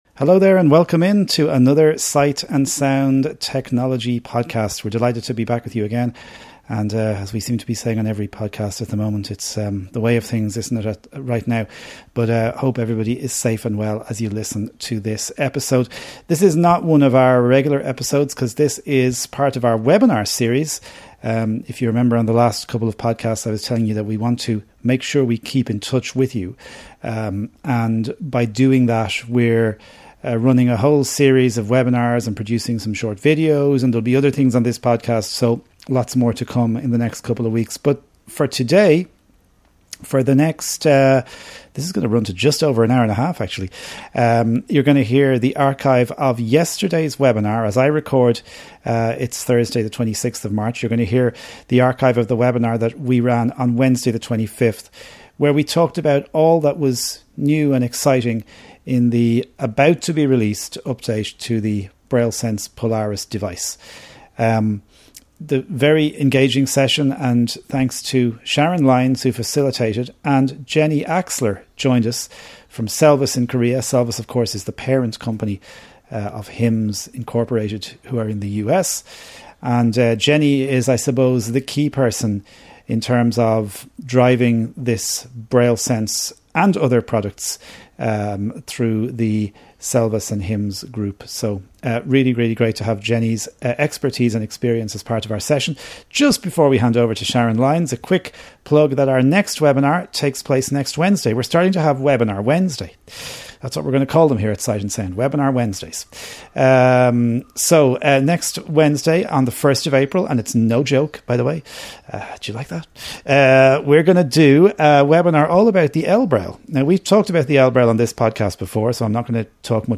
This episode contains the archive of our webinar from March 25th 2020, where we explored and demonstrated some of the new highlights in the up-coming Braille Sense Polaris software update.